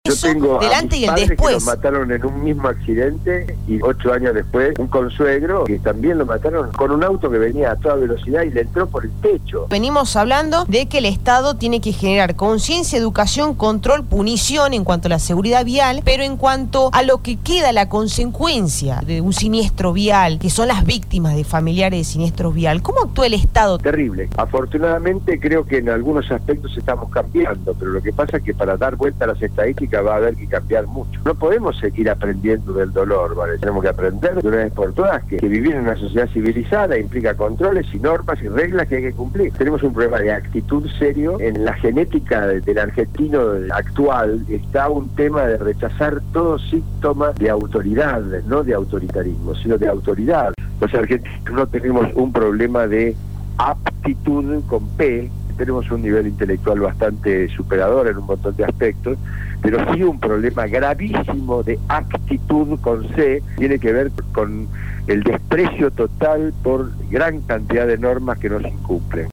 “Yo tengo a mis padres que los mataron en un mismo accidente, otro año después a un consuegro también lo mataron con un auto que venía a toda velocidad y le entró por el techo”, expresó González a Radio Dinamo.
IGNACIO-GONZALEZ-DIRECTOR-CONSULTOR-DE-LA-AGENCIA-DE-SEGURIDAD-VIAL.mp3